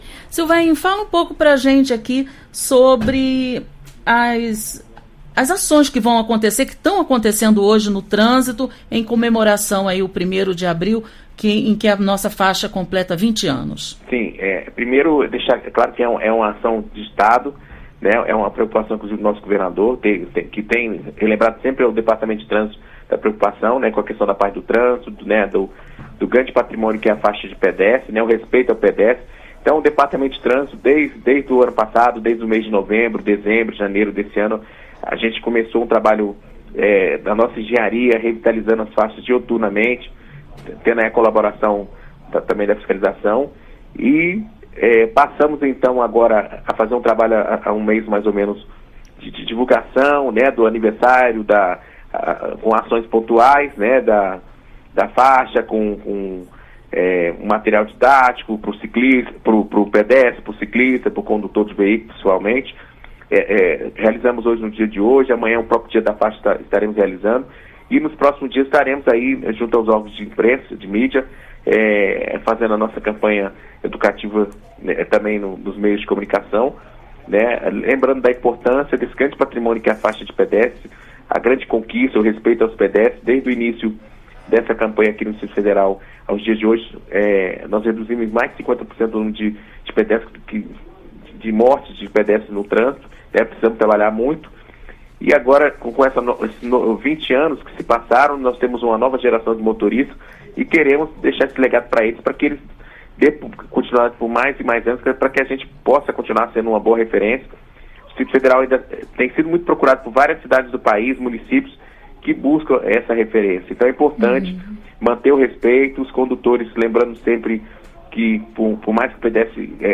Entrevista: DF reduz em 50% mortes de pedestres no trânsito com obrigatoriedade de parar na faixa